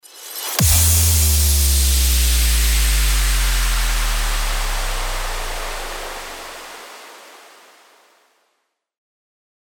FX-798-CHIMED-IMPACT
FX-798-CHIMED-IMPACT.mp3